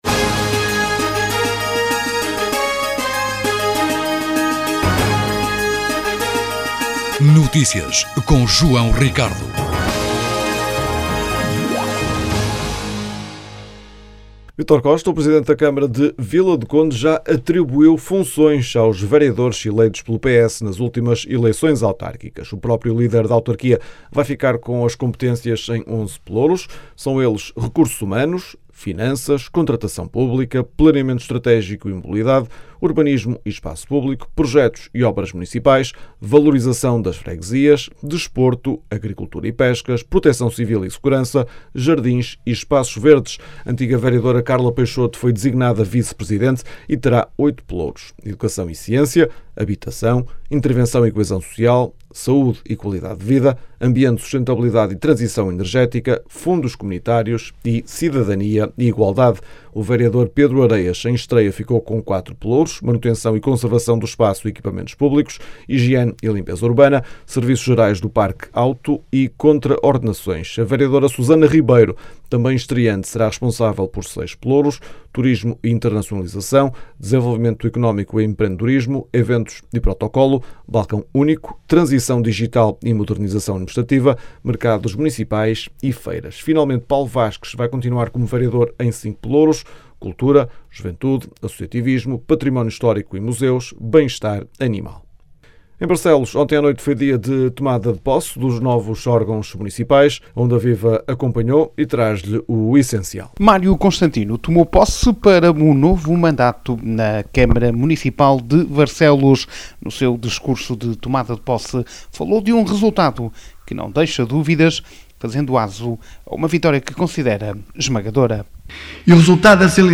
Mário Constantino inicia novo mandato à frente da Câmara de Barcelos Detalhes Categoria: Notícias Regionais Publicado em quarta-feira, 05 novembro 2025 13:13 Escrito por: Redação Mário Constantino tomou posse para um novo mandato como presidente da Câmara Municipal de Barcelos. A cerimónia marcou o arranque do novo ciclo autárquico 2025–2029.